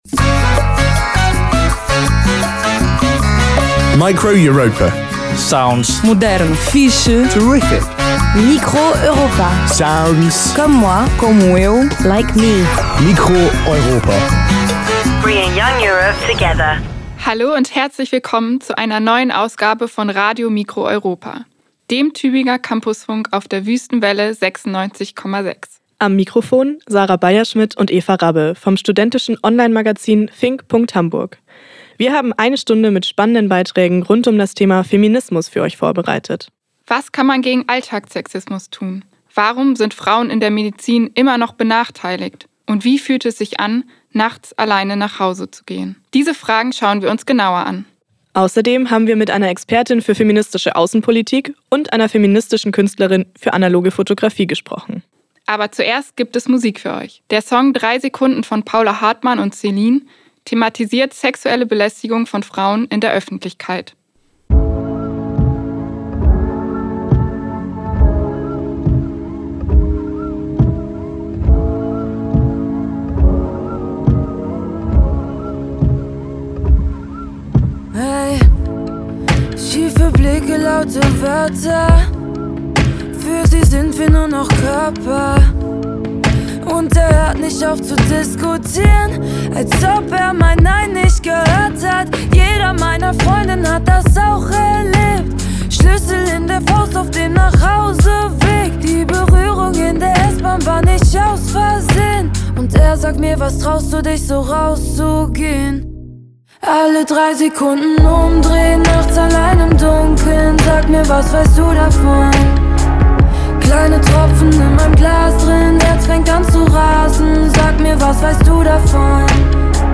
Dazu gibt’s spannende Talks mit einer Expertin für feministische Außenpolitik und einer kreativen Künstlerin, die analoge Fotografie neu denkt.
Form: Live-Aufzeichnung, geschnitten